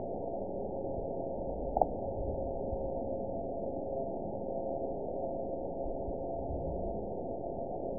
event 916793 date 01/31/23 time 05:23:13 GMT (2 years, 3 months ago) score 8.25 location TSS-AB01 detected by nrw target species NRW annotations +NRW Spectrogram: Frequency (kHz) vs. Time (s) audio not available .wav